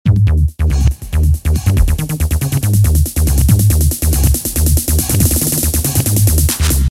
标签： 120 bpm Trance Loops Groove Loops 1.49 MB wav Key : Unknown
声道立体声